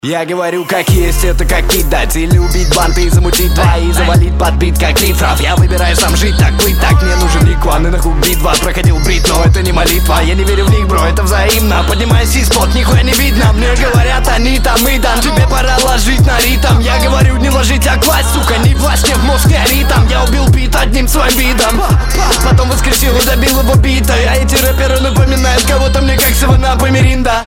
Хип-хоп
Лютая читка!